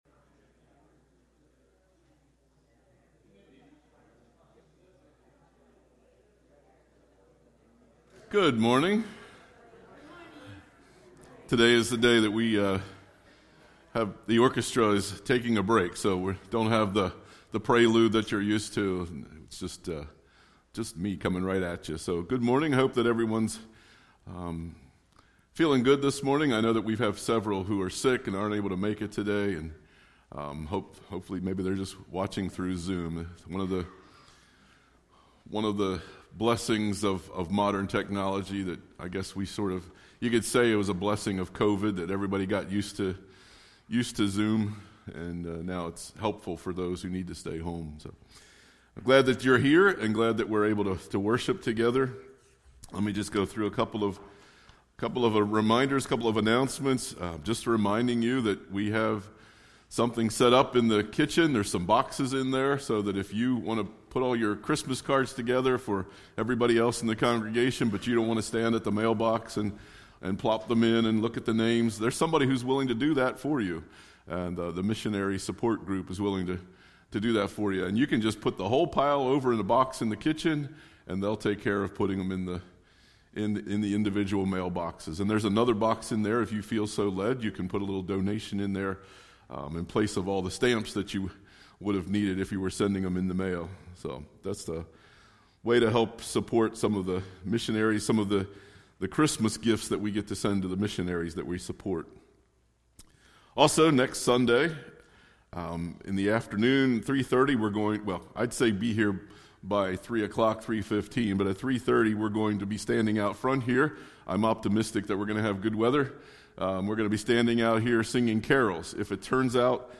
1 Sunday Worship December 15, 2024 53:17